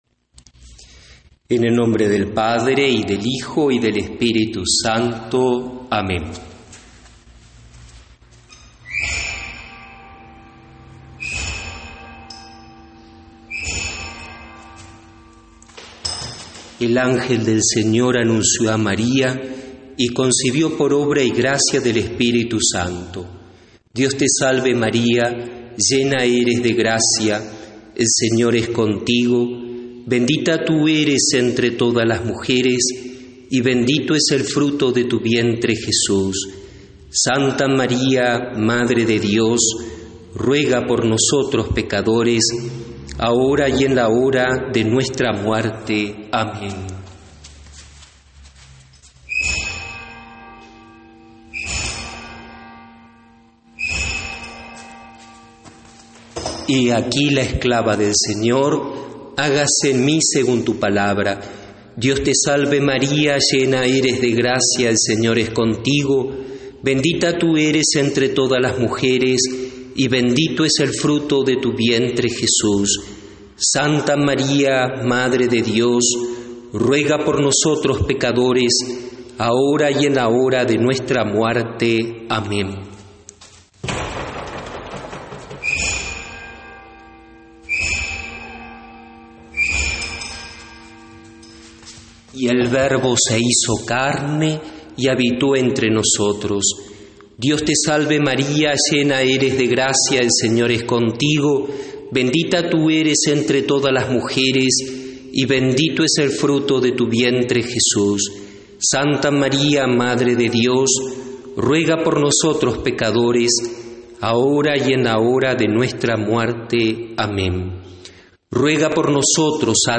Campanadas de la catedral santo Domingo de Nueve de Julio convocan a rezar el ‘Angelus’
Desde este viernes se han escuchado a las 8 de la mañana, a las 12 hs. y a las 19 hs.,  -lo mismo ha ocurrido este sábado –  campanadas de la iglesia catedral Santo Domingo de Guzmán de Nueve de Julio y muchos vecinos se peguntaban el motivo.